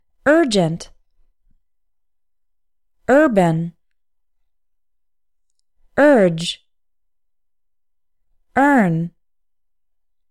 English words starting with U – “er” sound